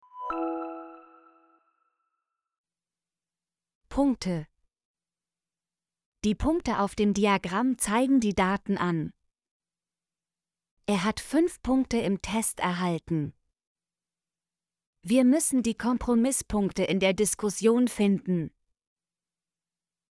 punkte - Example Sentences & Pronunciation, German Frequency List